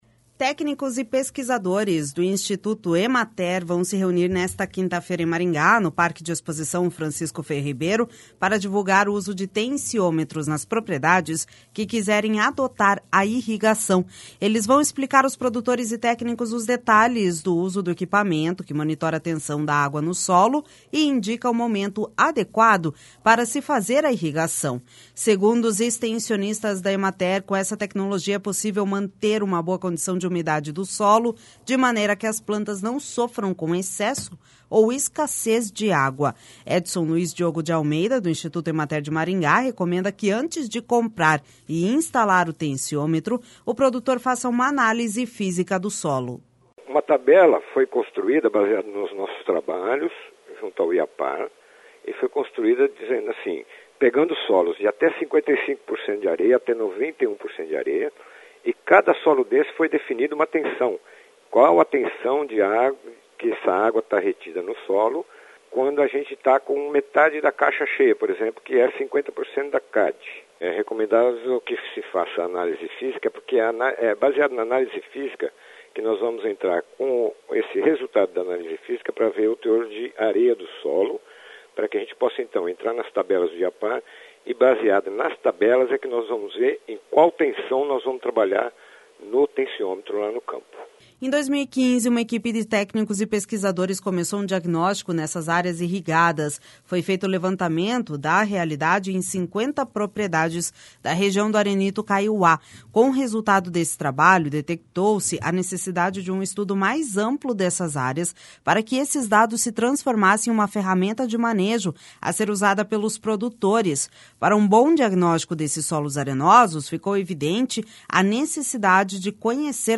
Portanto, o equipamento se mostrou viável como ferramenta para ser usada no manejo da irrigação. (Repórter: